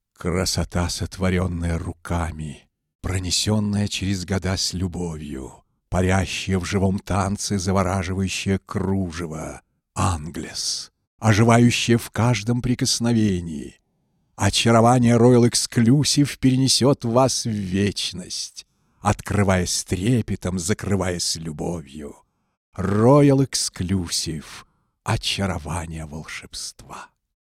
Warmer samtiger Bariton
Sprechprobe: eLearning (Muttersprache):